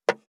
562魚切る,肉切りナイフ,まな板の上,
効果音